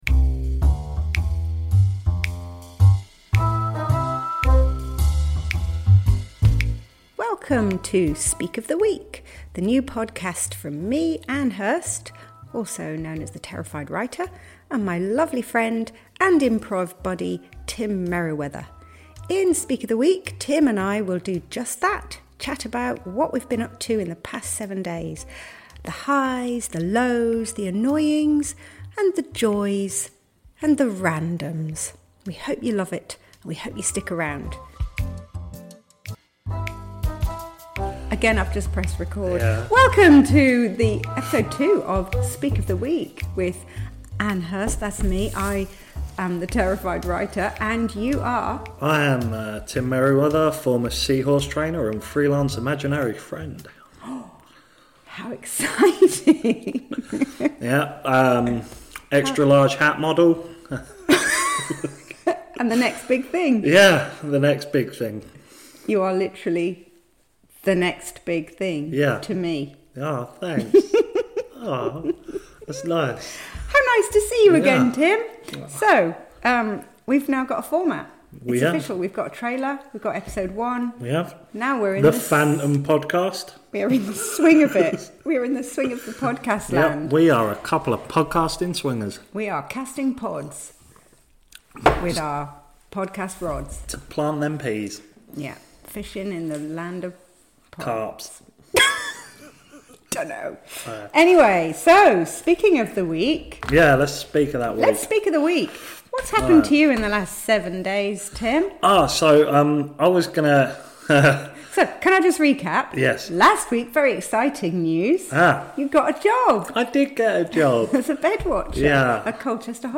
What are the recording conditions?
Ok, so we have a format, the audio is sorted and the best we can say about this episode is: we're getting there 🤨